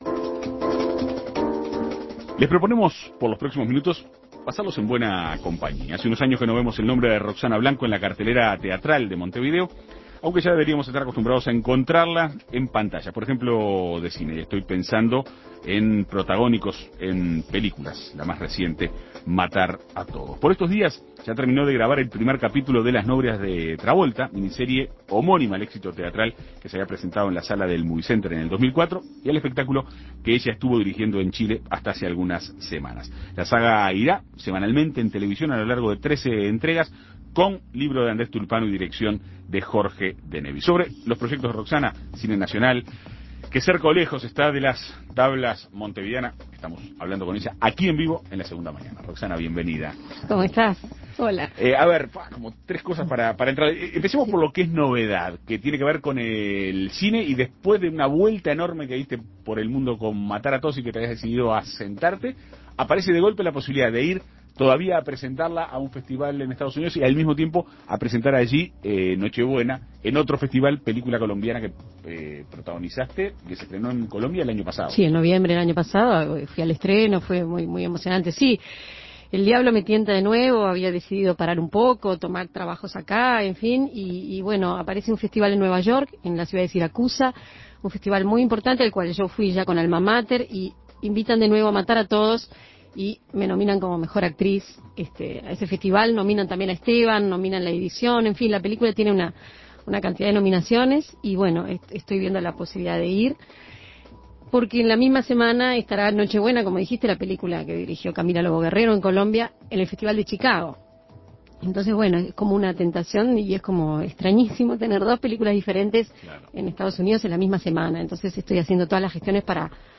En Perspectiva Segunda Mañana habló con Blanco sobre sus proyectos, el cine nacional y qué tan cerca está de volver a las tablas montevideanas.